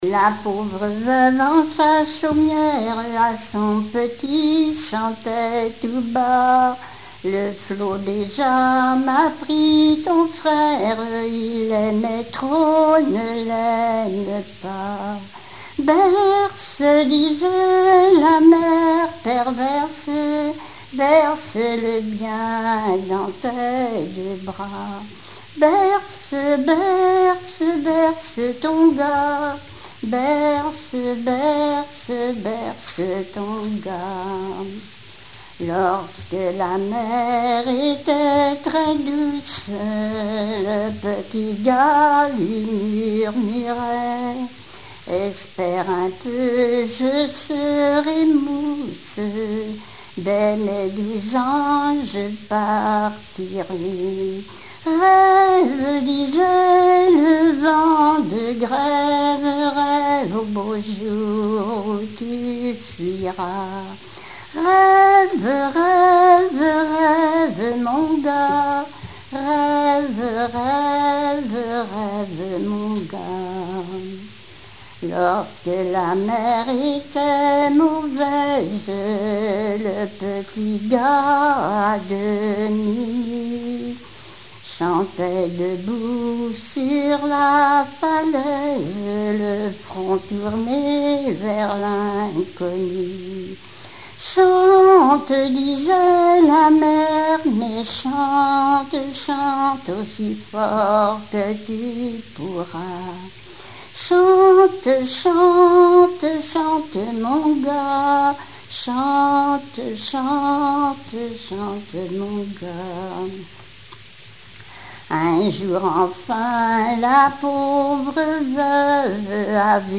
Genre strophique
Répertoire de chansons populaires et traditionnelles
Pièce musicale inédite